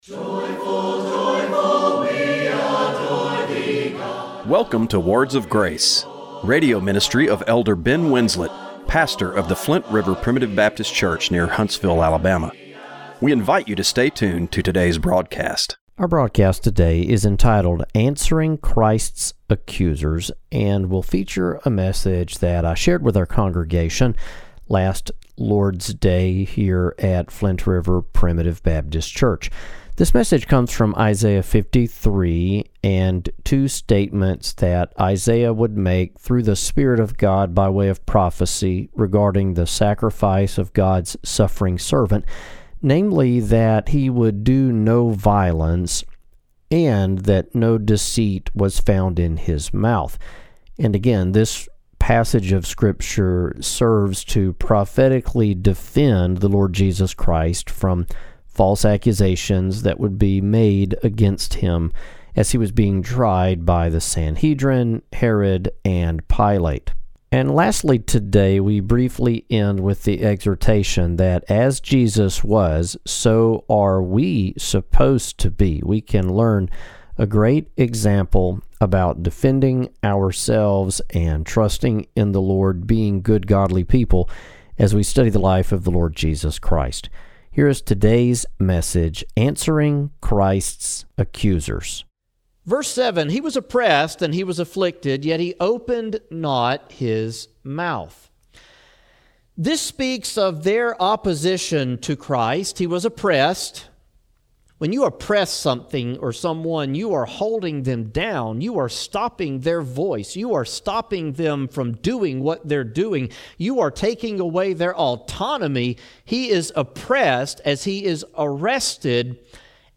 Radio broadcast for May 4, 2025.